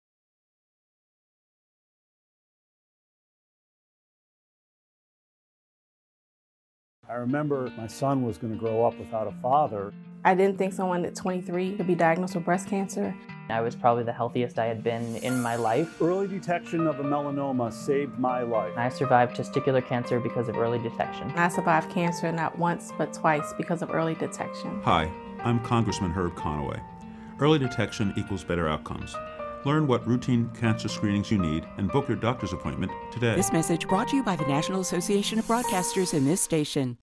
Representatives Herb Conaway (NJ-03), LaMonica McIver (NJ-10), and Rob Menendez (NJ-08) recorded radio and television PSAs covering a multitude of important subjects.
Rep. Herb Conaway (NJ-03)